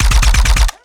Added more sound effects.
GUNAuto_RPU1 B Burst_04_SFRMS_SCIWPNS.wav